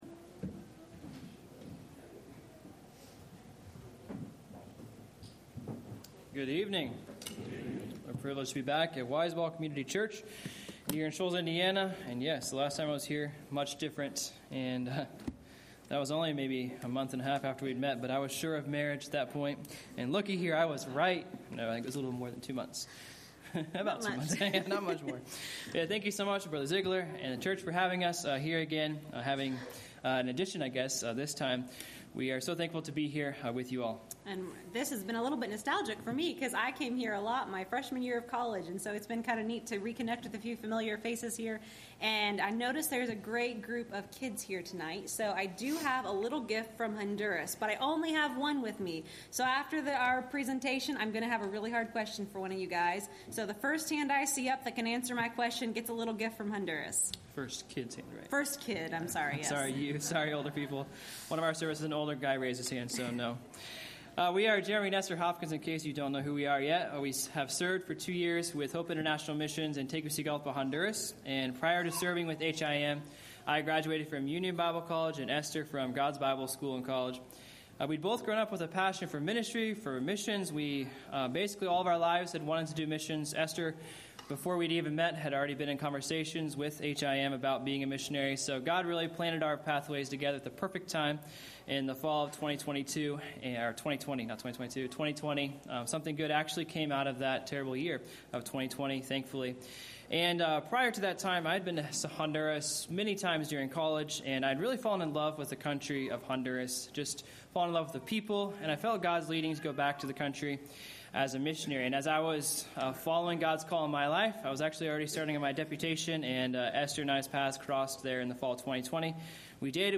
Missionary Service